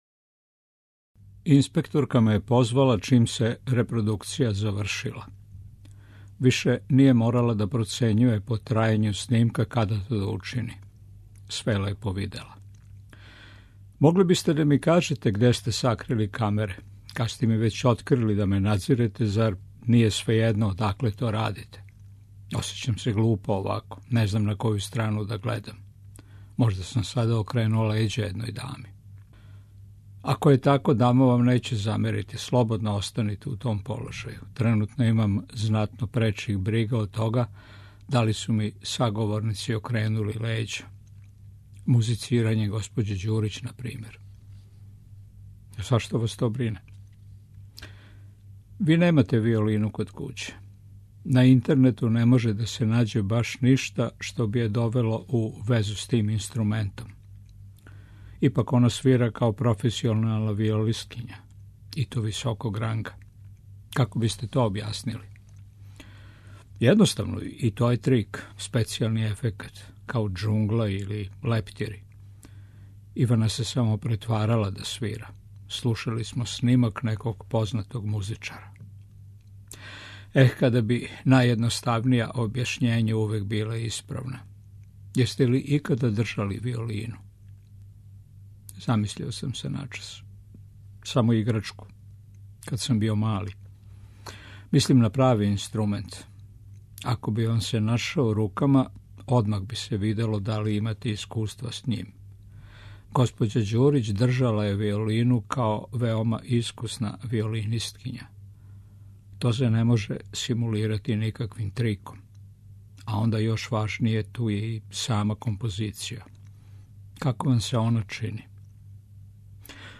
Књига за слушање
Роман ће прочитати сам аутор, а пошто се заврши његово емитовање на Трећем програму, обједињени снимак целог дела биће на располагању слушаоцима на нашем подкасту.